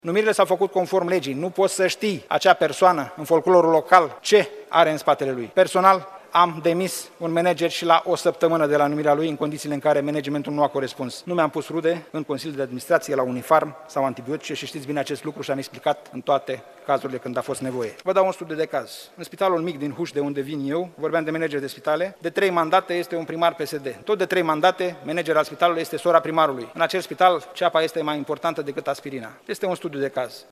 Camera Deputaților dezbate, marți, moțiunea simplă depusă de opoziție împotriva ministrului Sănătății.